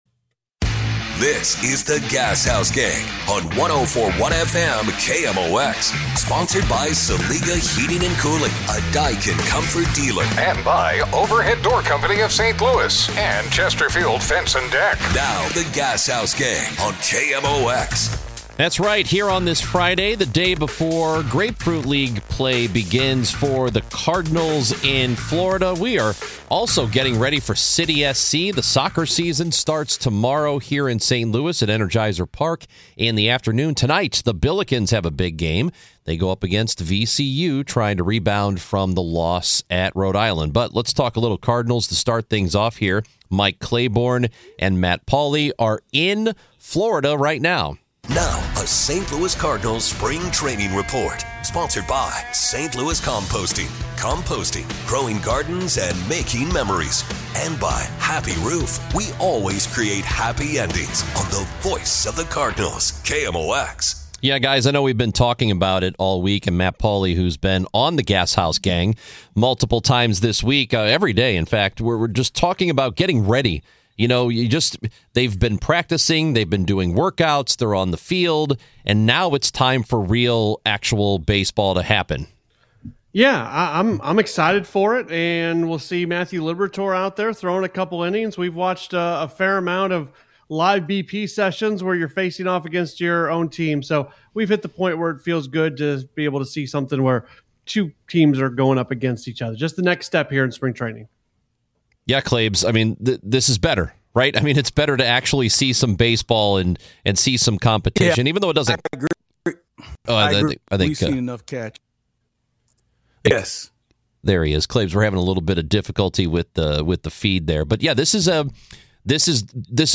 from Cardinals spring training in Jupiter, Florida